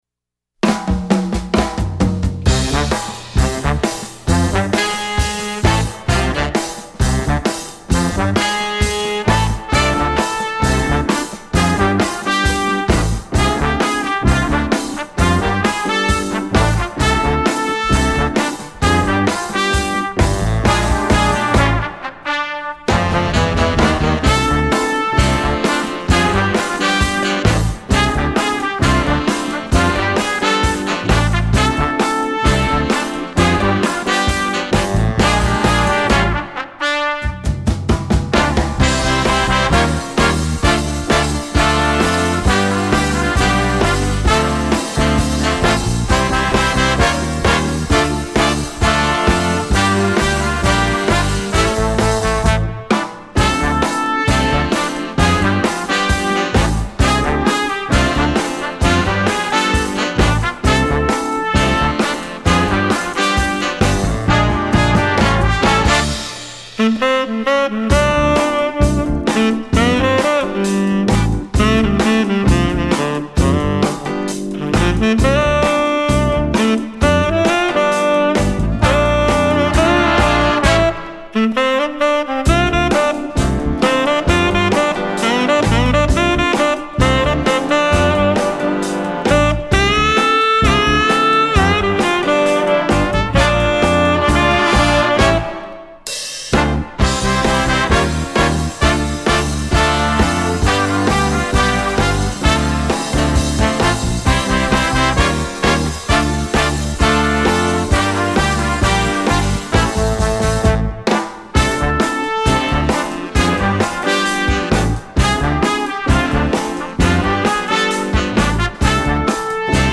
Instrumentation: jazz band